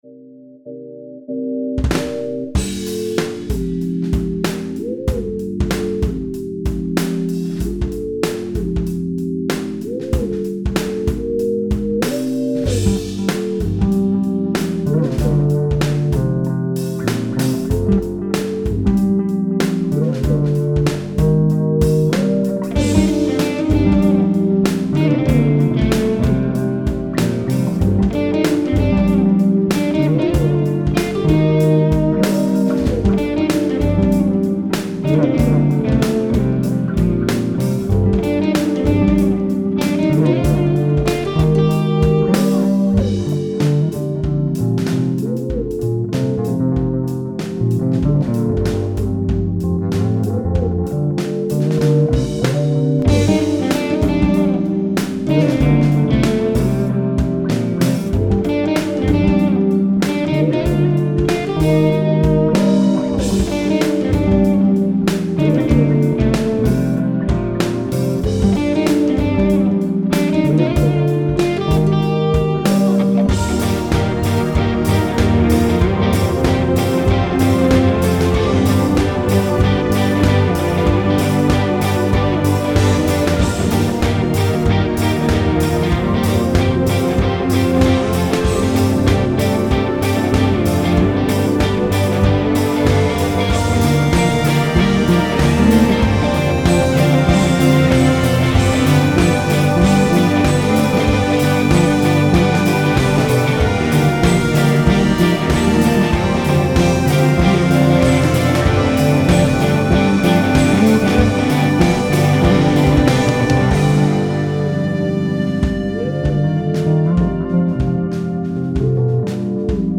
Young and talented singer